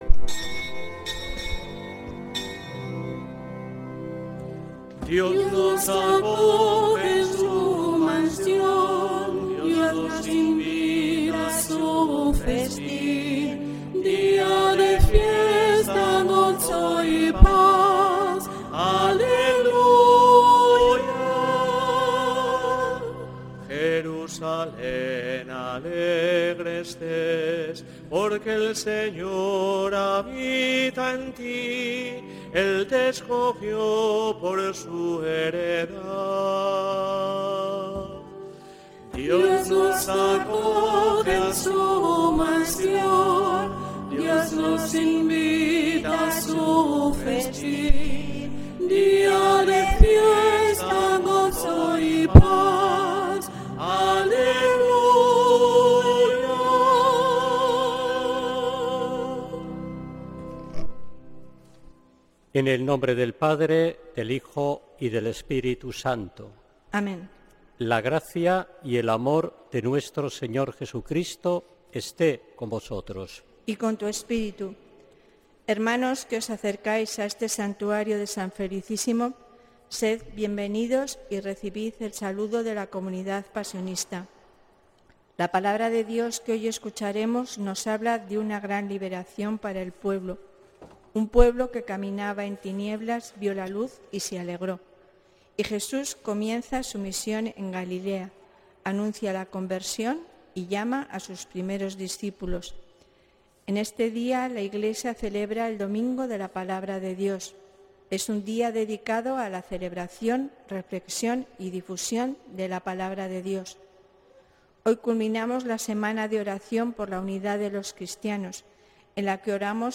Santa Misa desde San Felicísimo en Deusto, domingo 25 de enero de 2026